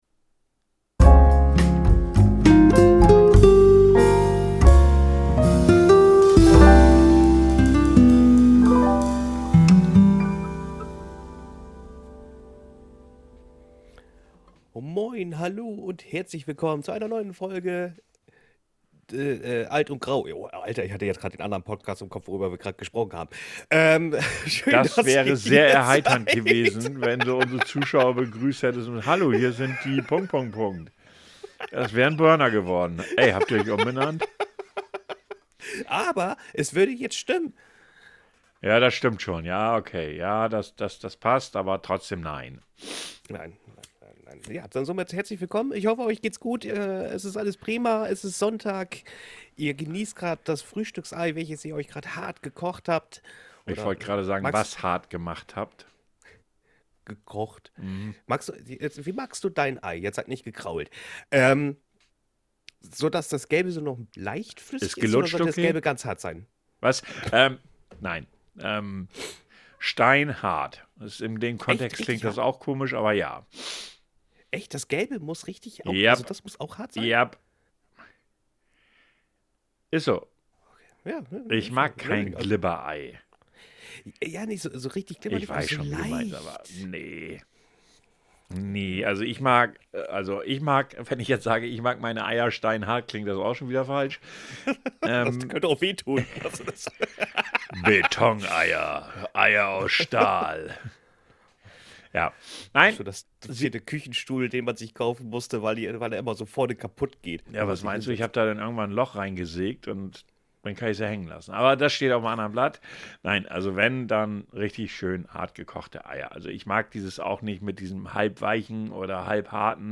hobbies e gestão de tempo 52:20 Play Pause 3d ago 52:20 Play Pause Прослушать позже Прослушать позже Списки Нравится Нравится 52:20 Nota: Esta é versão dobrada (traduzida) por Inteligência Artificial do episódio original, gravado em inglês (que está disponível também no 45 Graus, lançado uma semana antes). Advertência: uma vez que a dobragem foi feita por Inteligência Artificial, contém inevitavelmente falhas.